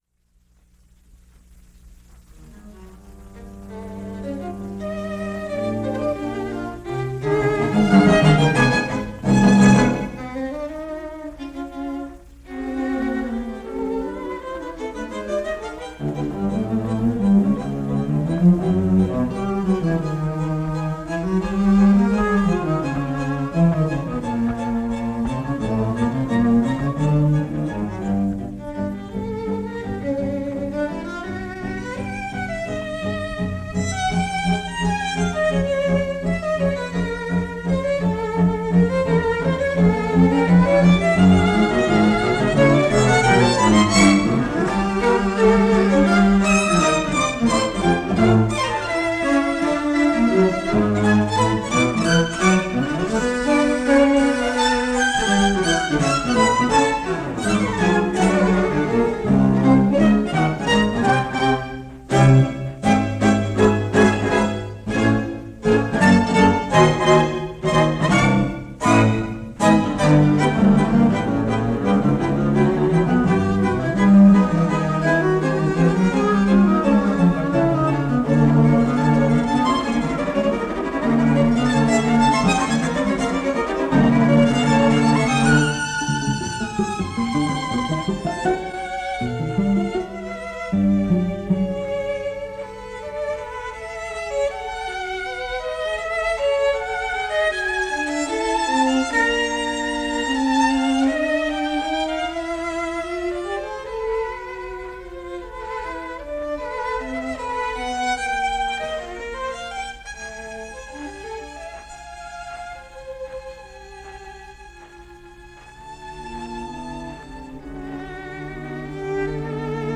unidentified String Quartet – Unidentified live performance
But I think it’s safe to say it’s from a concert performance somewhere in Southern California because the discs come from a small studio in Pasadena.